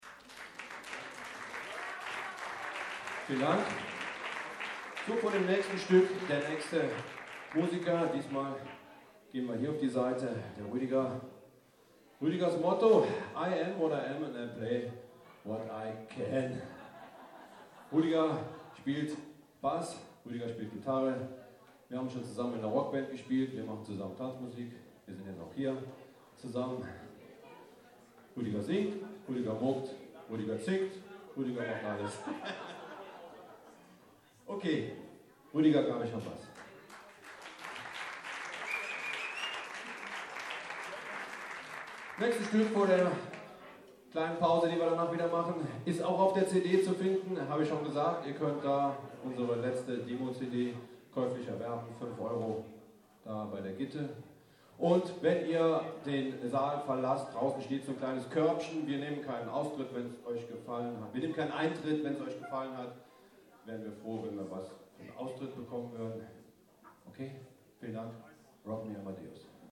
18 - Ansage.mp3